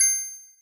Coins (10).wav